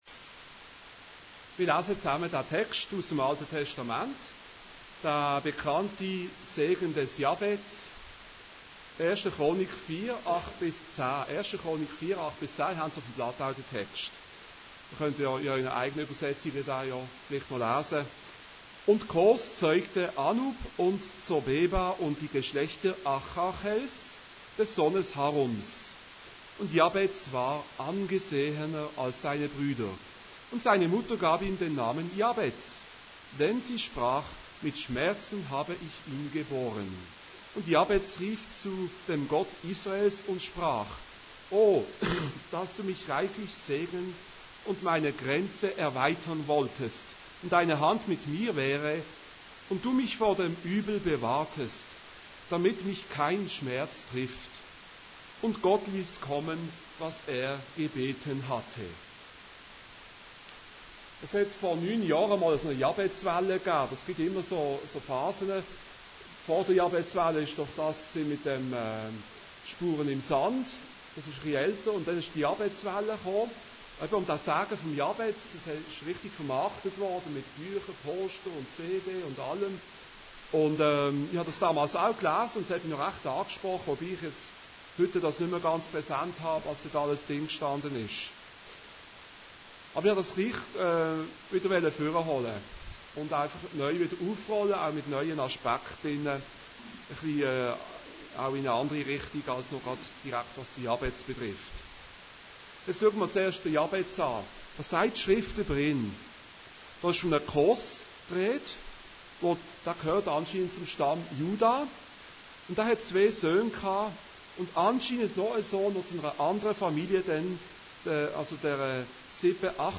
Series: Allgemeine Predigten